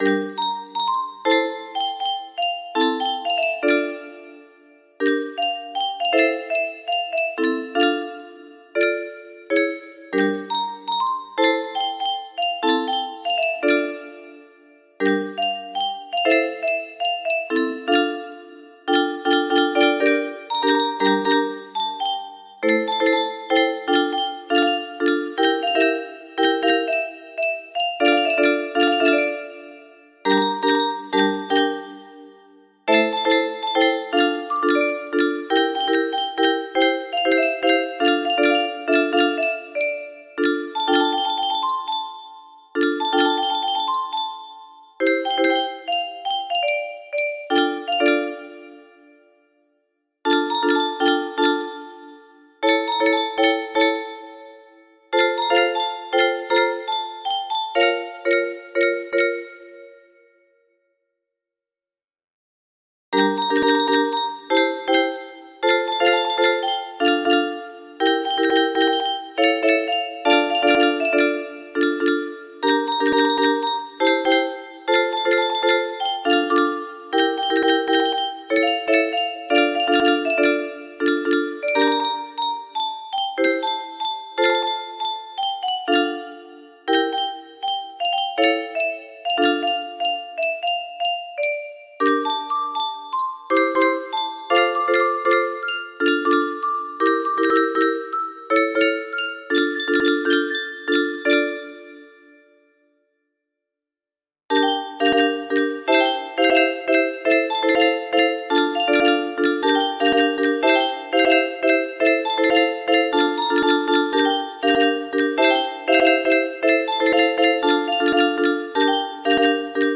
854114 music box melody